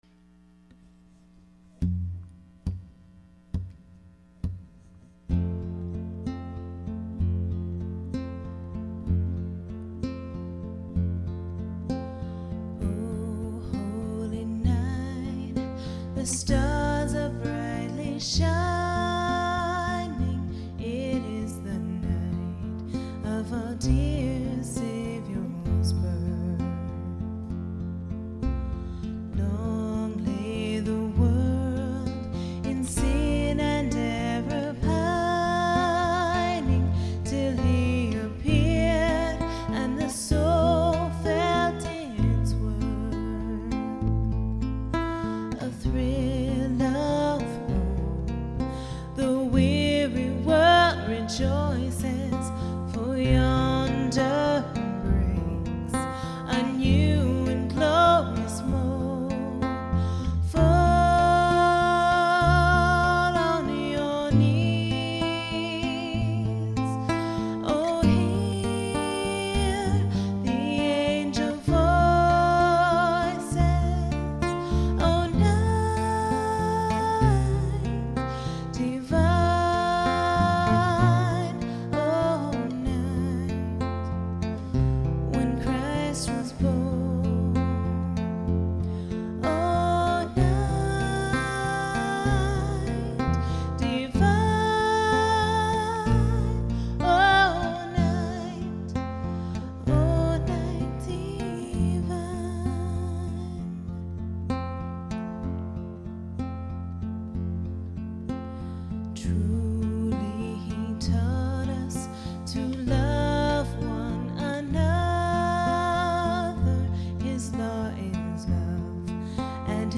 For those of you still in the Christmas spirit for one reason or another, here are a couple of carols dad and I have put together over the last couple of years!